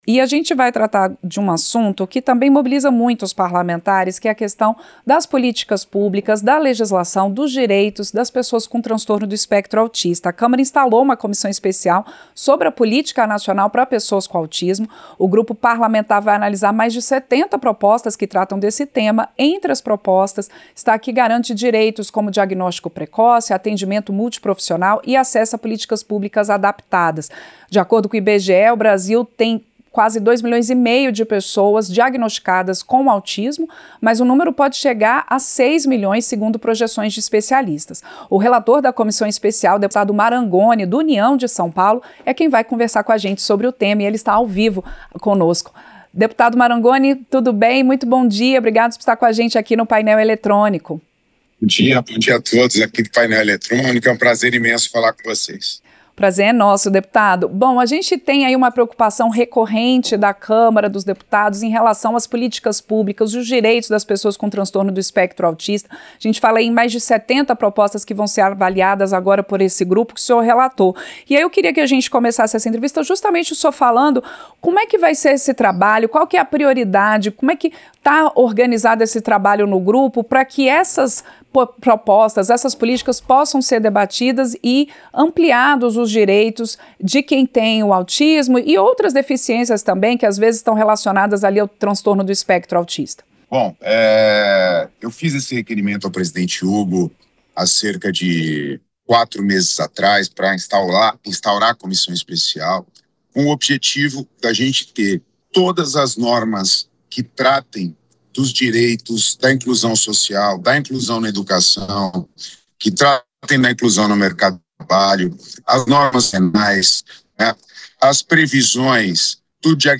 Entrevista - Dep. Marangoni (União-SP)